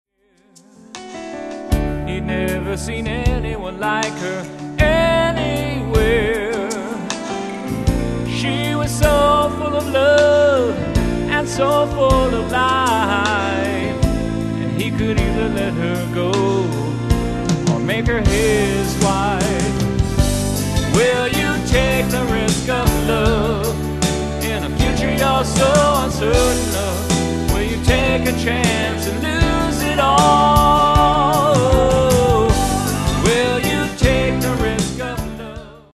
drums, percussion
guitar
background vocals
bass, Keyboards, vocals